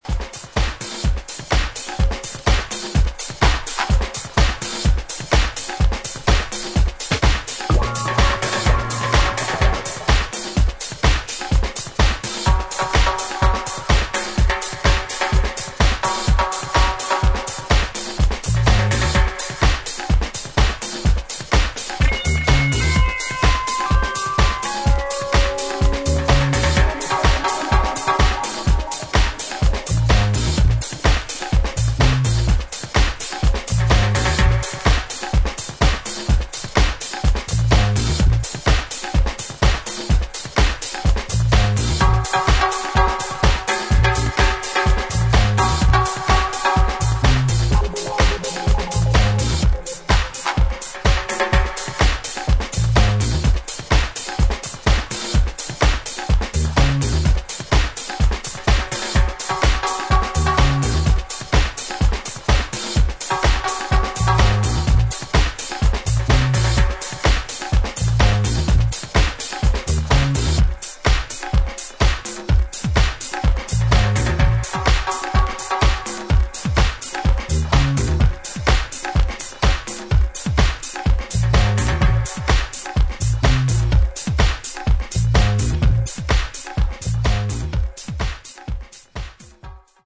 パーカッシブな生音サンプリングのグルーヴにディスコベースとダブ・シンセが絡む